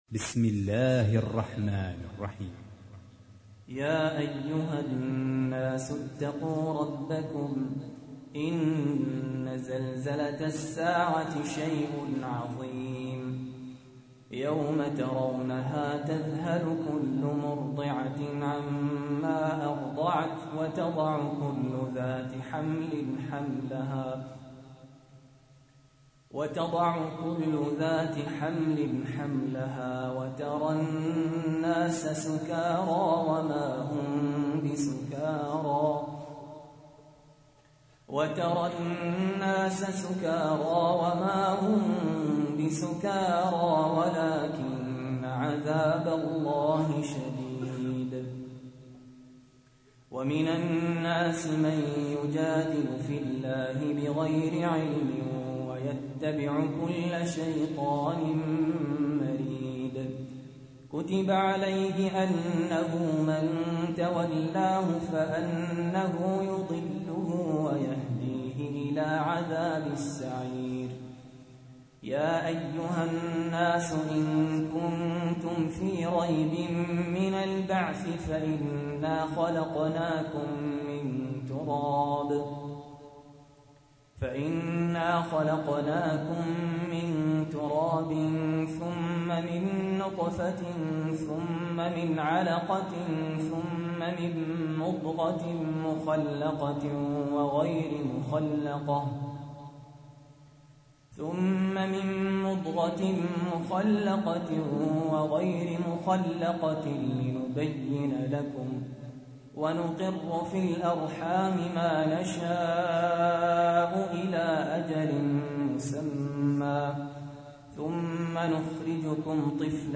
تحميل : 22. سورة الحج / القارئ سهل ياسين / القرآن الكريم / موقع يا حسين